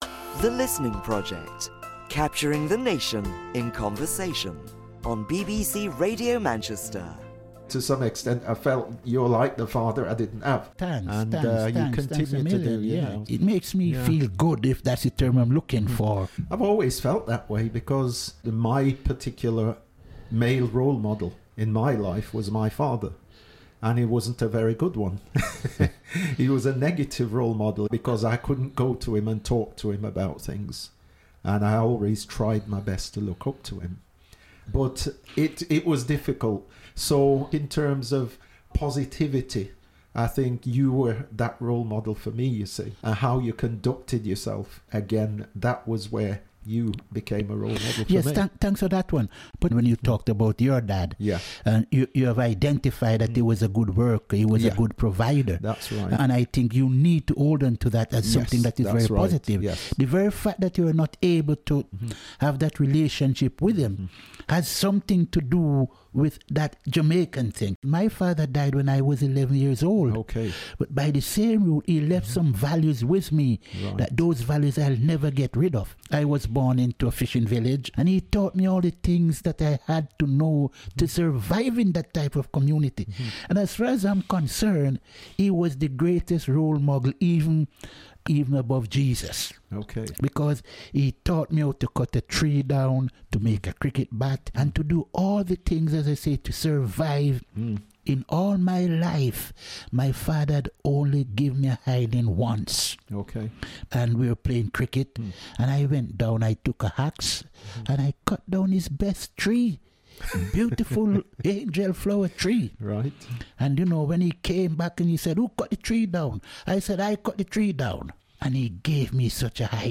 We've recorded chats between close friends and family - and here's the latest.